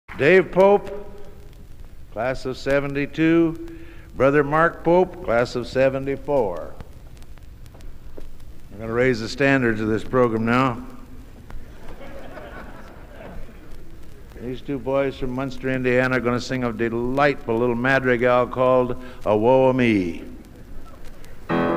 Genre: | Type: Director intros, emceeing